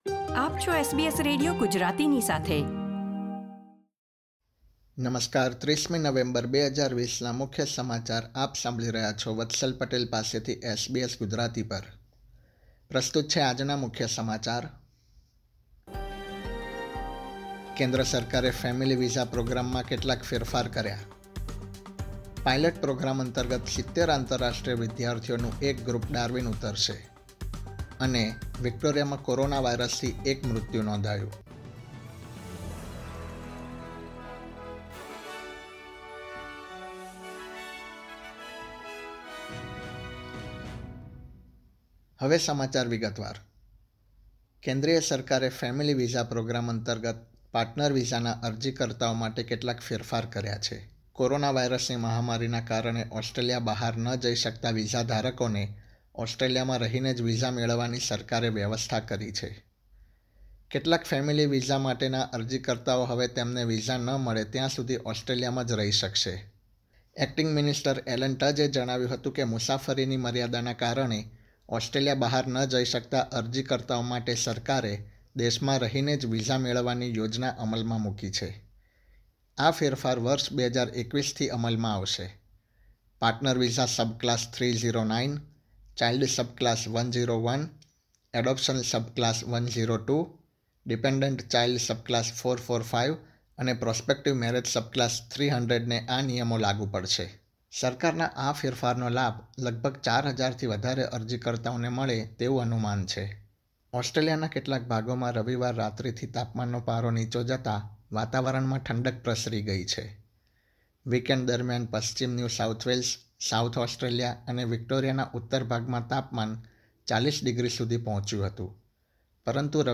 ૩૦ નવેમ્બર ૨૦૨૦ના મુખ્ય સમાચાર
gujarati_3011_newsbulletin.mp3